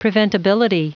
Prononciation du mot : preventability